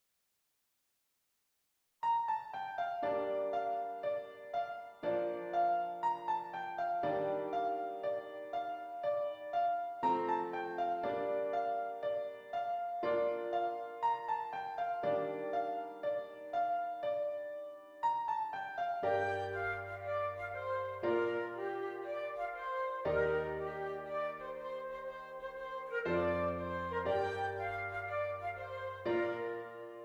Flute Solo with Piano Accompaniment
Does Not Contain Lyrics
B Flat Major
Moderately